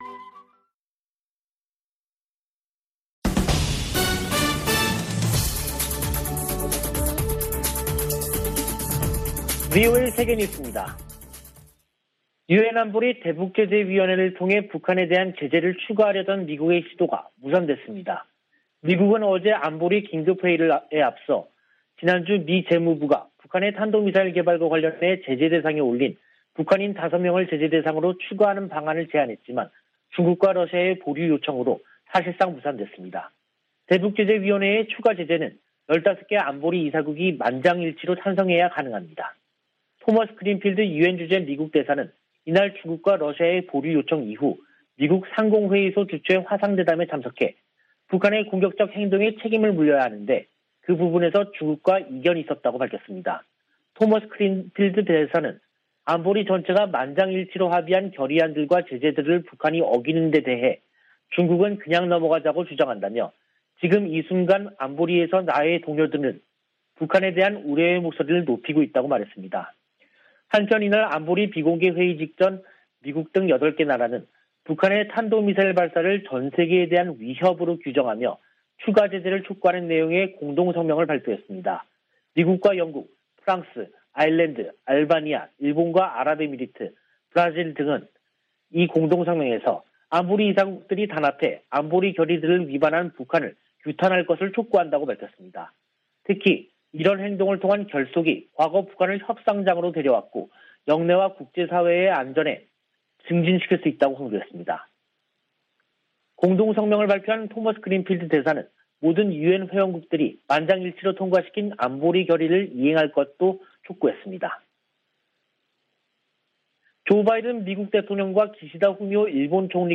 VOA 한국어 간판 뉴스 프로그램 '뉴스 투데이', 2022년 1월 21일 2부 방송입니다. 미국 등 8개국이 북한의 탄도미사일 발사를 전 세계에 대한 위협으로 규정하고 유엔에서 추가 제재를 촉구했습니다. 백악관은 북한이 무기 시험 유예를 해제할 수 있다는 뜻을 밝힌 데 대해, 대량살상무기 개발을 막을 것이라고 강조했습니다. 북한이 선대 지도자들의 생일을 앞두고 열병식을 준비하는 동향이 포착됐습니다.